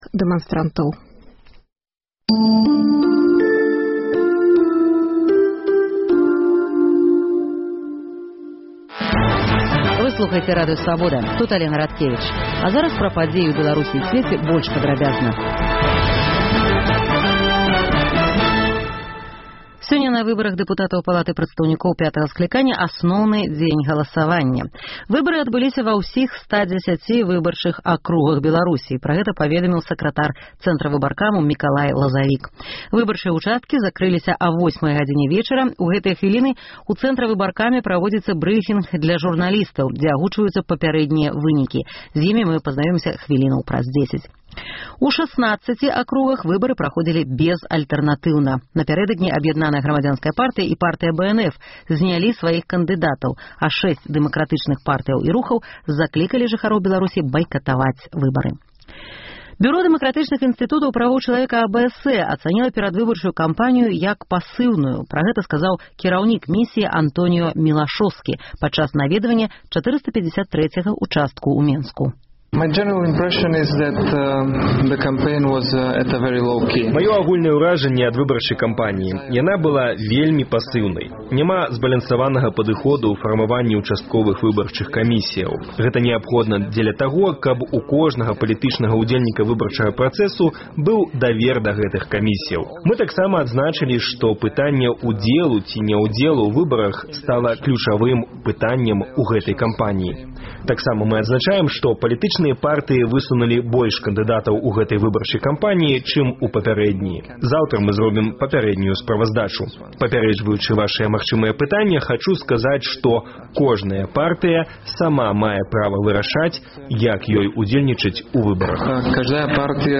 Навіны Беларусі і сьвету. Паведамленьні нашых карэспандэнтаў, званкі слухачоў, апытаньні ў гарадах і мястэчках Беларусі